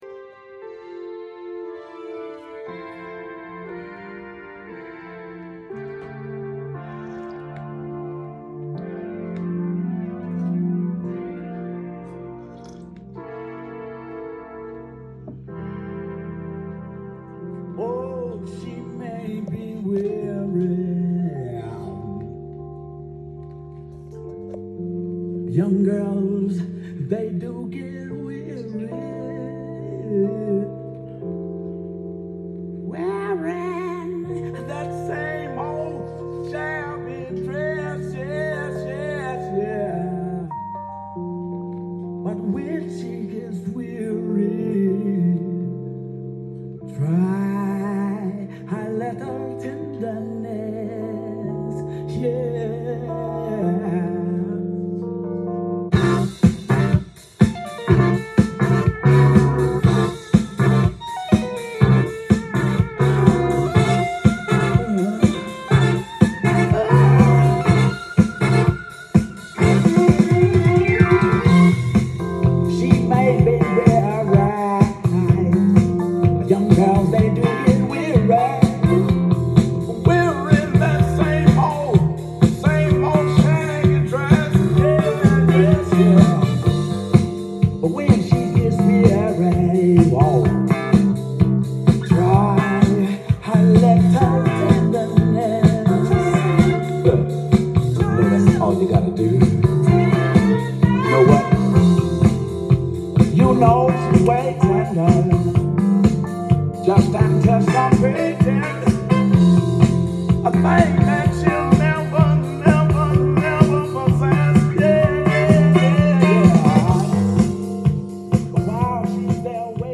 ジャンル：FUNK
店頭で録音した音源の為、多少の外部音や音質の悪さはございますが、サンプルとしてご視聴ください。